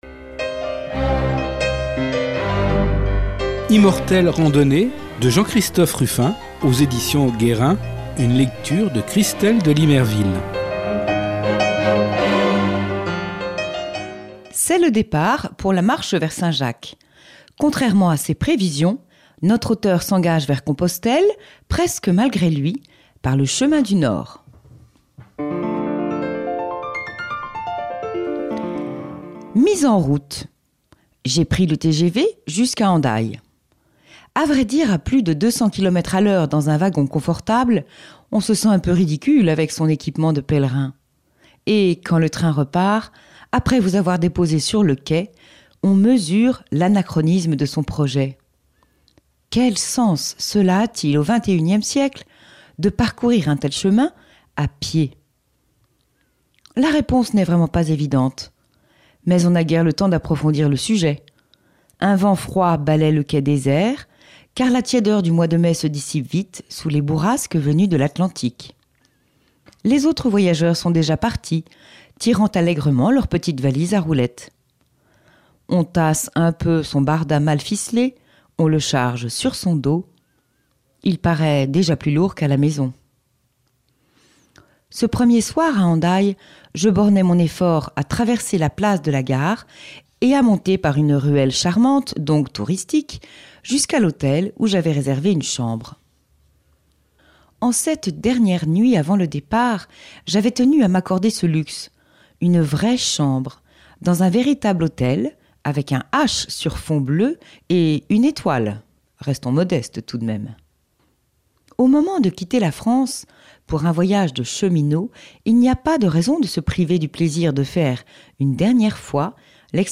lecturesuivie